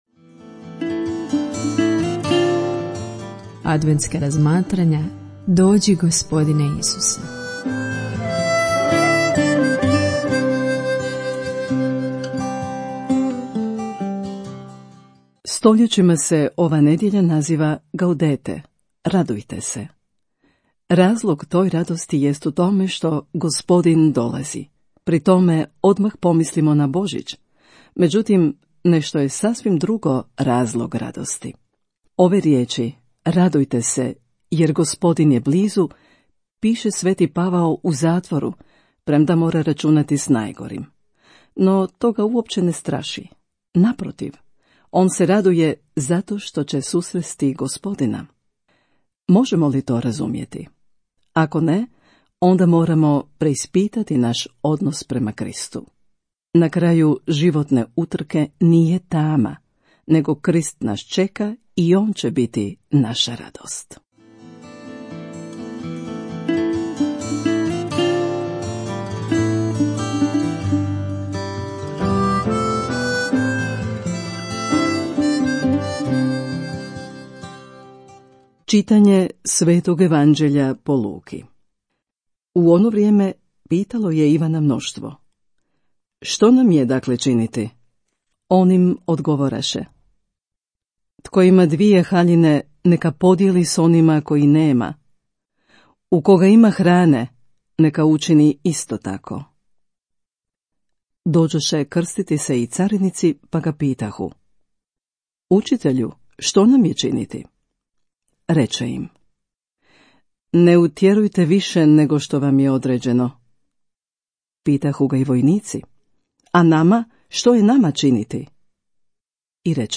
„DOĐI GOSPODINE ISUSE“ – adventska razmatranja